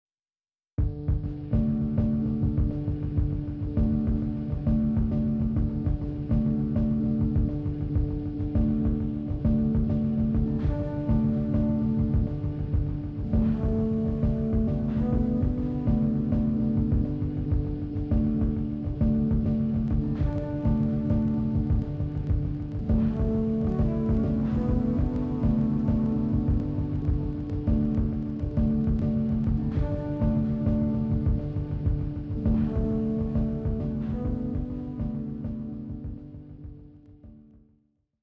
An example with RX 8 De-click, removing clicks post reverb and delay with grace
I turn it off in the middle so you can hear the difference.